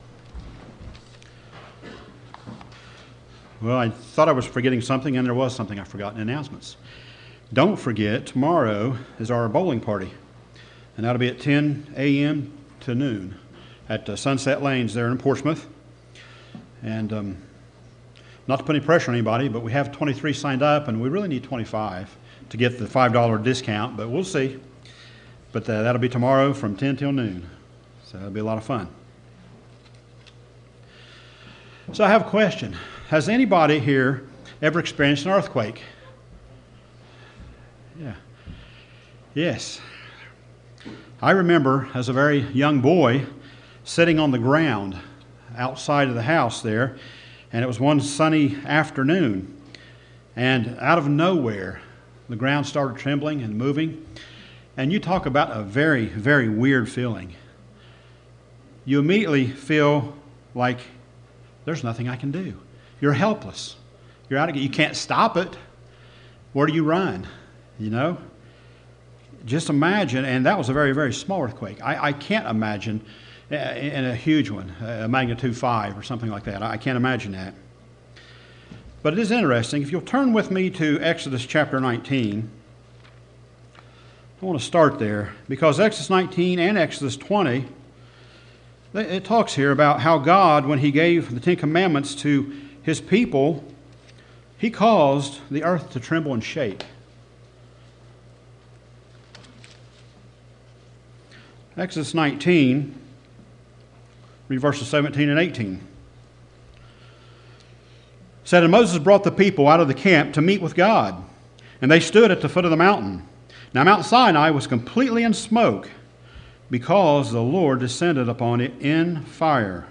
Sermons Keeping The Sabbath Day Holy
Given in Portsmouth, OH